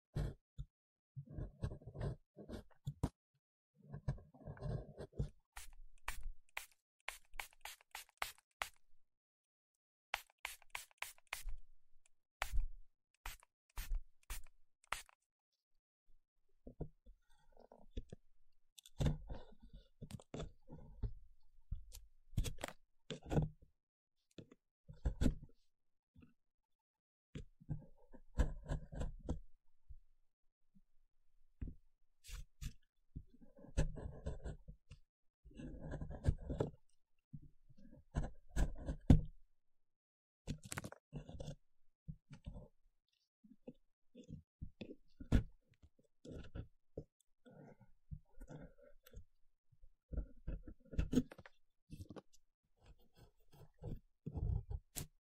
ASMR Excavation Dinosaur Fossil sound effects free download
ASMR Excavation Dinosaur Fossil - Rough and Intense Sounds (No Talking)_009